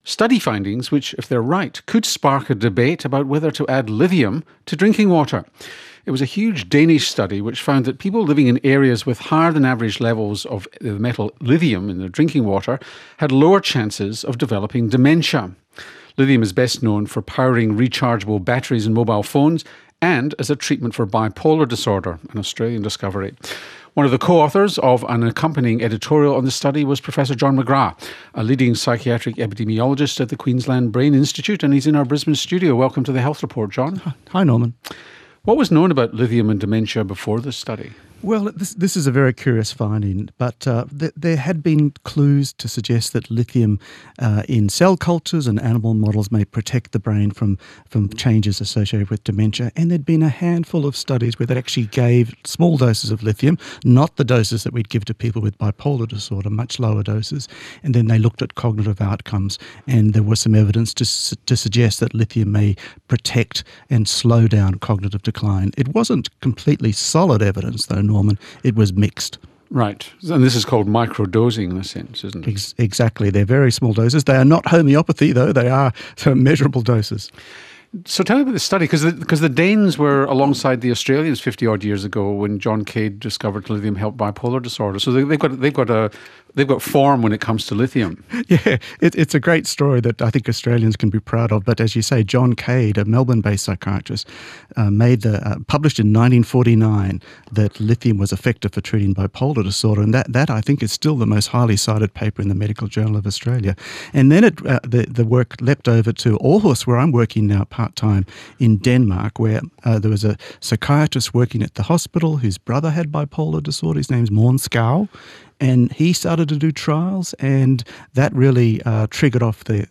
talked to ABC’s Dr Norman Swan on the Health Report about the finding that lithium added to drinking water has been found to be linked to a lower risk of dementia.